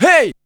RX HEY SHOUT.wav